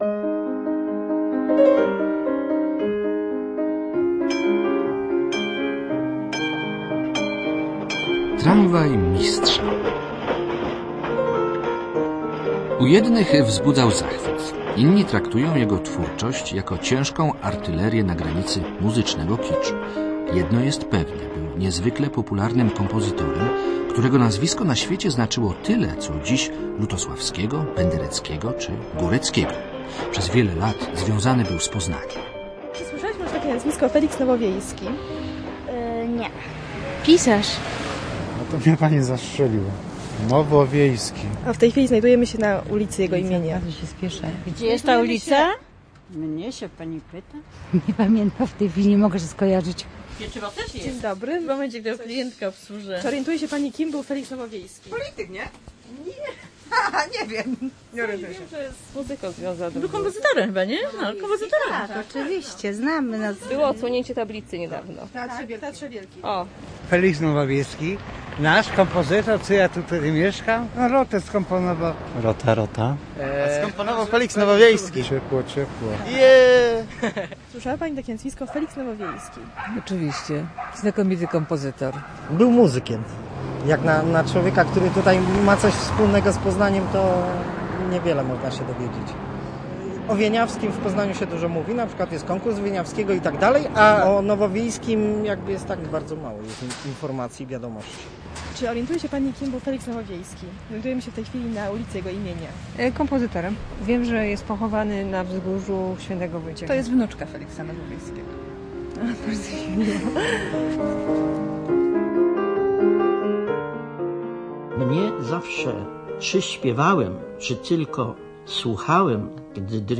Tramwaj Mistrza - audycja dokumentalna
Ilustracja muzyczna aranżacje utworów Nowowiejskiego